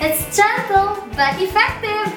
gbe Meme Sound Effect